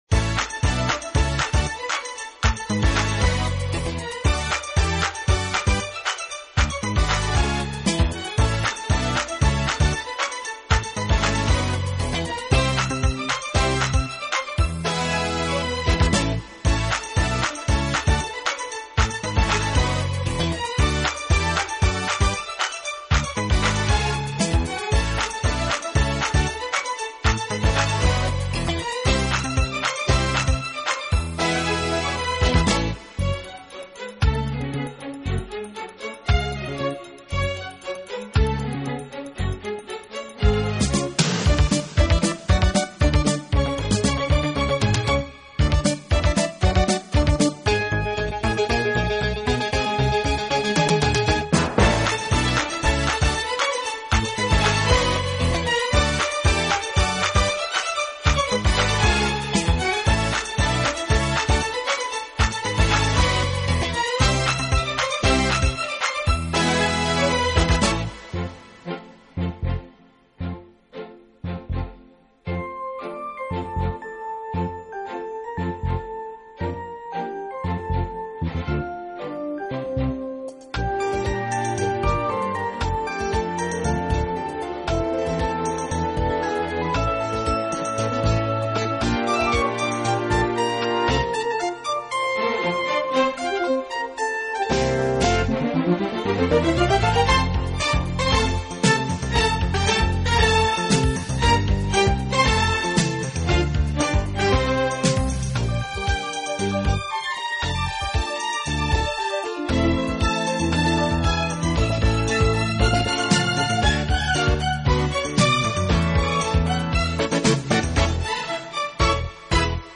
音乐类型：Instrumental, Easy Listening